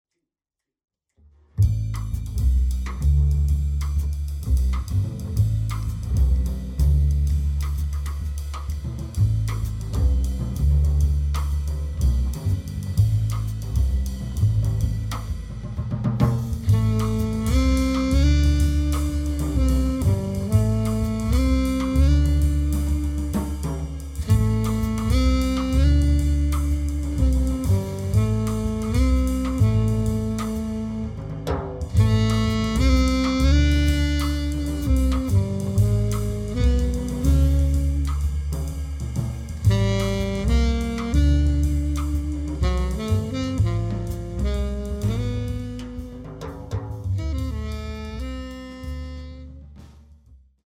sax
bass
drums